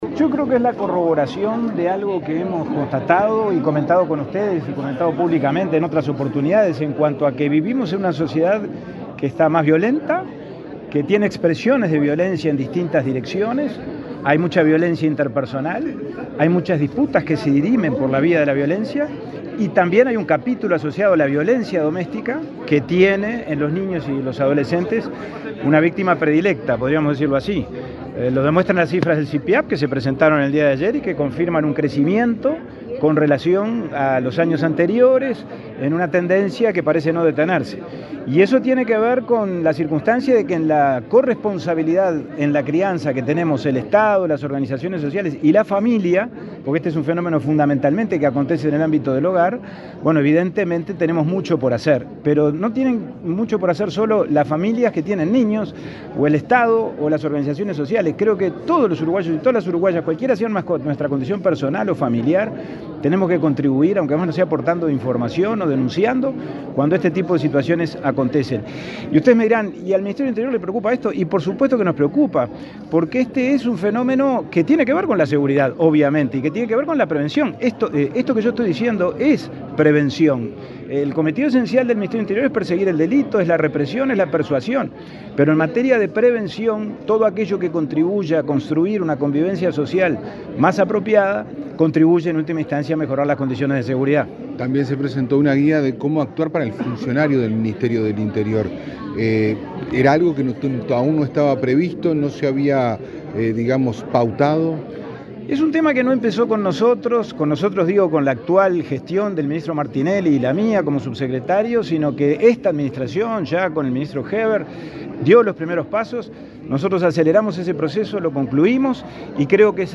Declaraciones del subsecretario del Interior, Pablo Abdala
Este jueves 25, el subsecretario del Interior, Pablo Abdala, dialogó con la prensa, luego de participar en el acto de presentación de los resultados